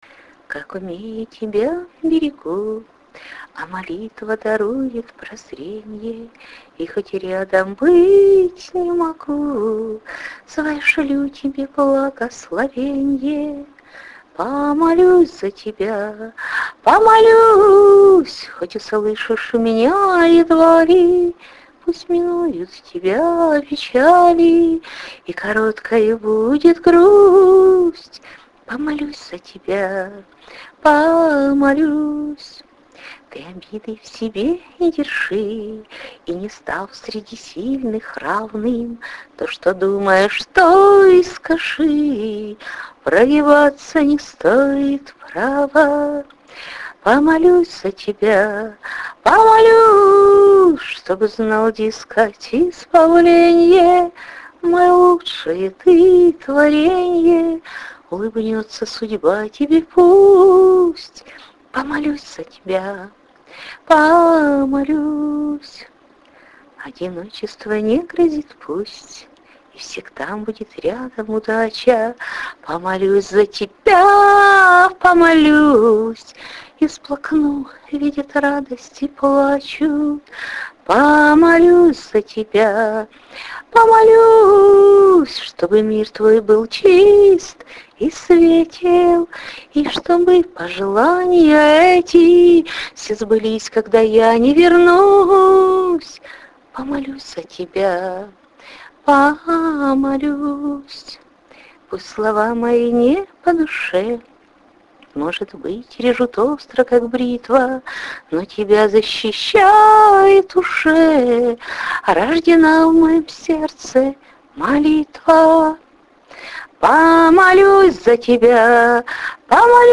Почему-то манера исполнения напомнила ВСВысоцкого; чрезвычайный ансамбль-текста,голоса и веры.